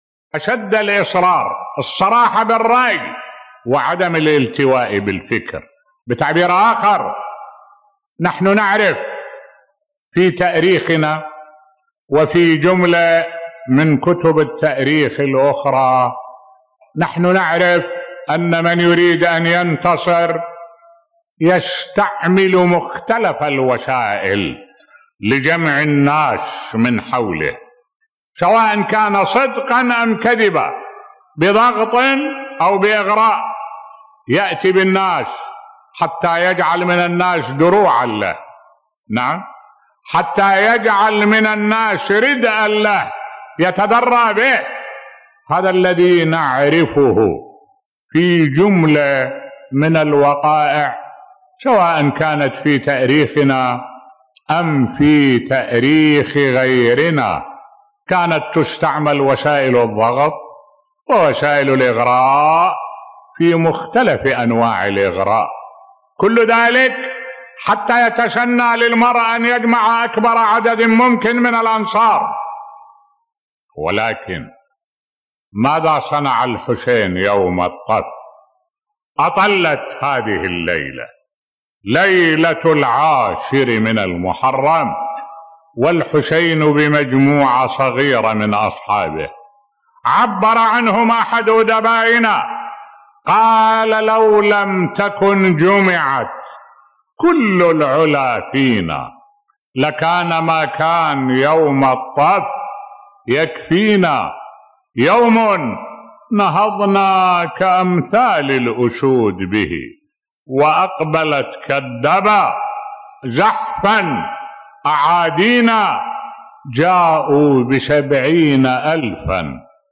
ملف صوتی الصراحة بالرأي وعدم الالتواء بالفكر عند الحسين (ع) بصوت الشيخ الدكتور أحمد الوائلي